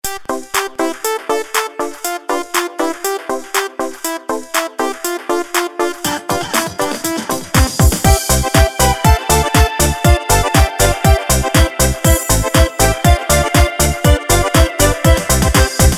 • Качество: 320, Stereo
без слов